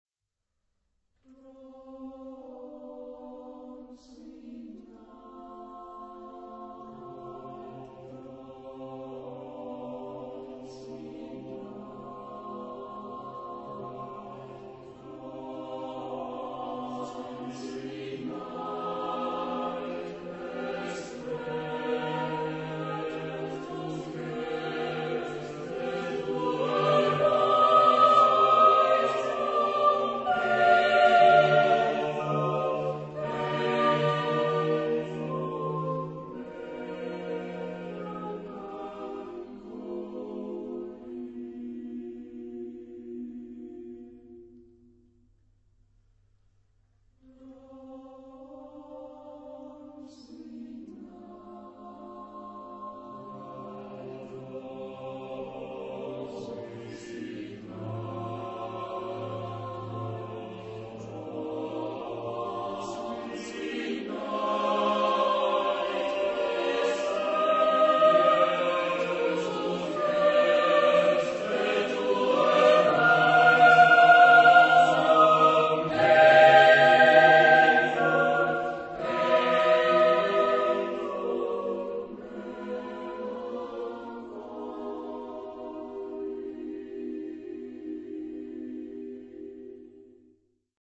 Tipo de formación coral: SATB  (4 voces Coro mixto )